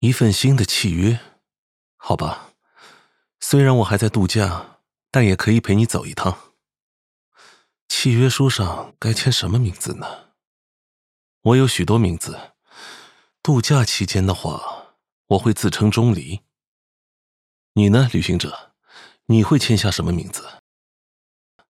VOICE: Calm and soothing (